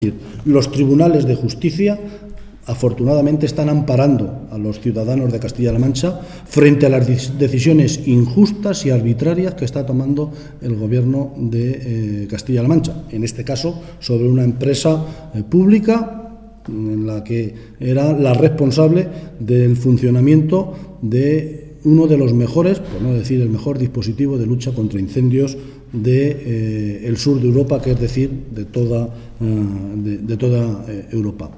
Martínez Guijarro respondía así a preguntas de los medios de comunicación, en Cuenca, sobre la decisión del Tribunal Superior de Justicia de Castilla-La Mancha (TSJCM) de anular el Expediente de Regulación de Empleo (ERE) que presentó la Empresa de Gestión Ambiental de Castilla-La Mancha (Geacam) y que afectaba a 75 trabajadores, que tendrán que ser readmitidos.
Cortes de audio de la rueda de prensa